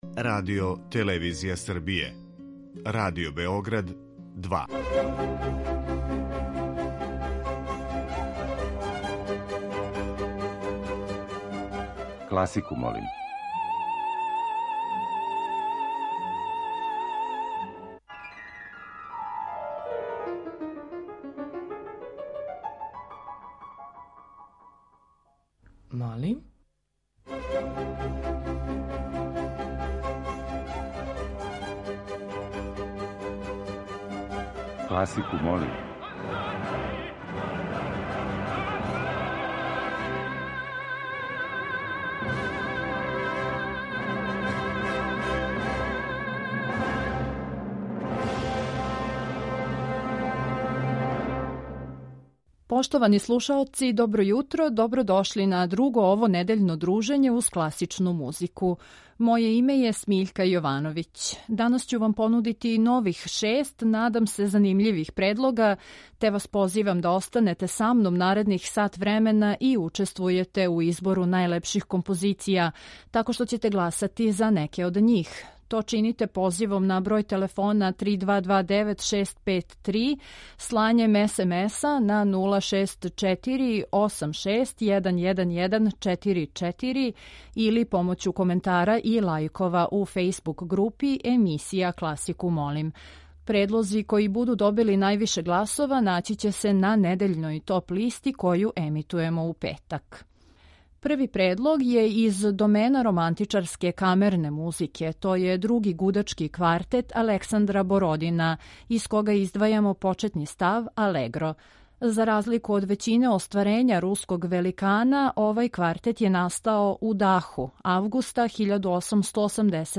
У петак ће бити објављена топ-листа остварења која су освојила највише гласова. преузми : 21.59 MB Класику молим Autor: Група аутора Стилски и жанровски разноврсни циклус намењен и широком кругу слушалаца који од понедељка до четвртка гласају за топ листу недеље.